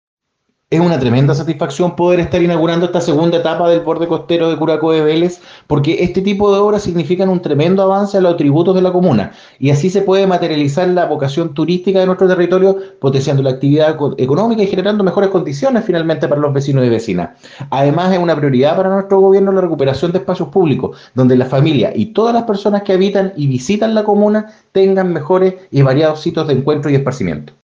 La alcaldesa Javiera Yáñez y el ministro de Obras Públicas, Juan Carlos García, encabezaron la concurrida ceremonia de inauguración de la segunda etapa del borde costero de la comuna de Curaco de Vélez,  que tuvo una inversión sectorial de más de 2 mil 500 millones de pesos.
Adicionalmente, el Seremi del MOP Daniel Olhabé manifestó que obras como estás, no solo son un aporte en materia de conectividad, sino que además significan un importante progreso en la calidad de los ciudadanos: